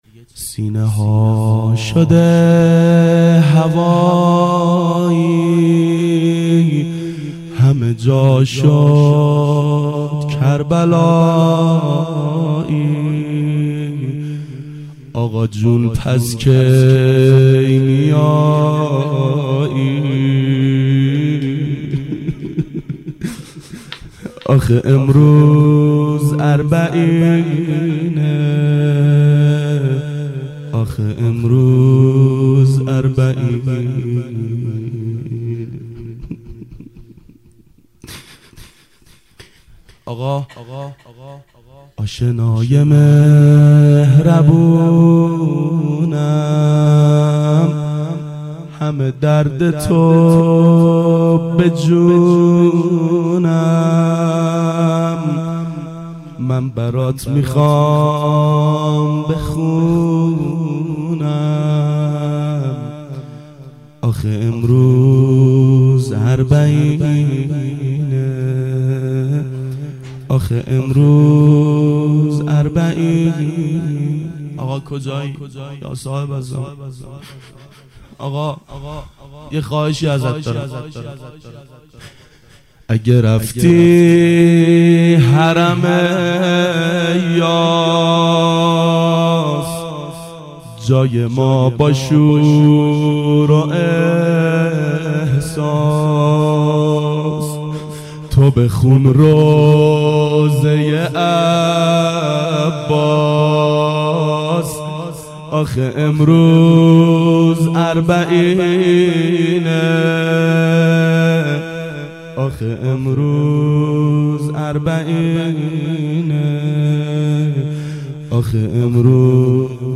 ظهر اربعین سال 1388 محفل شیفتگان حضرت رقیه سلام الله علیها